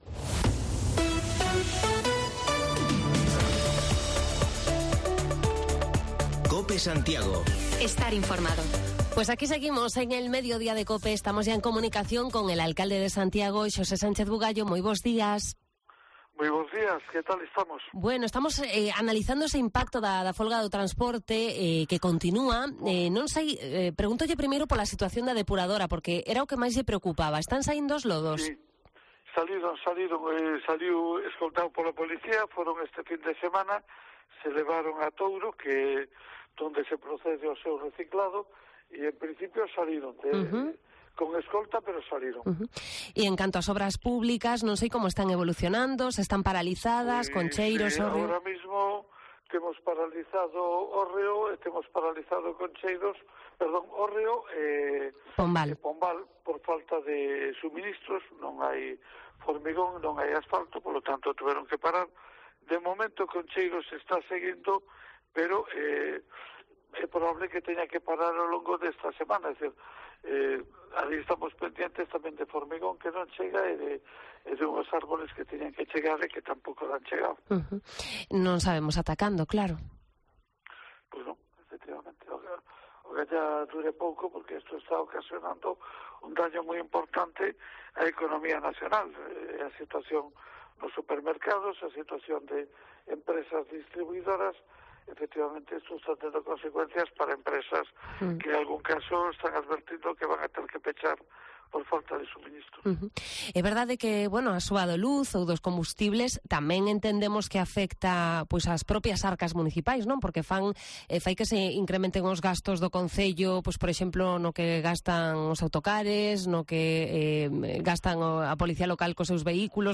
AUDIO: Repasamos la actualidad local con el alcalde de Santiago, Xosé Sánchez Bugallo